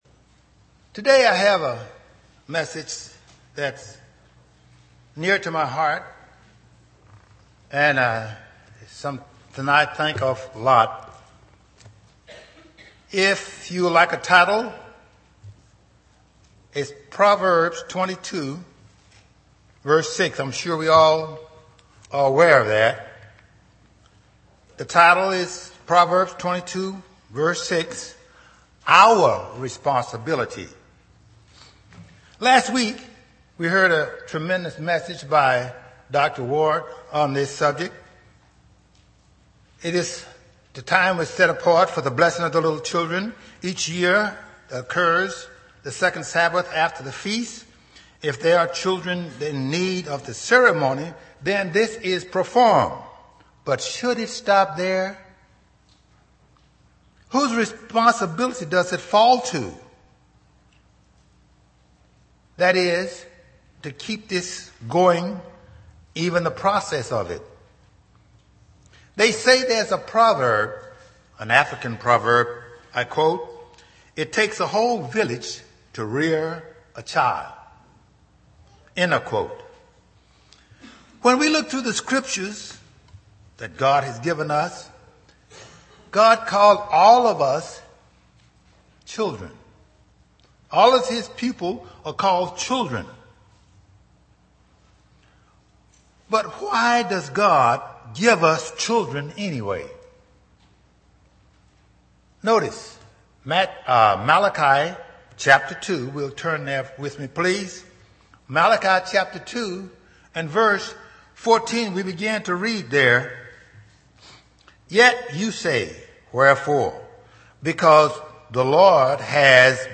UCG Sermon Studying the bible?
Given in East Texas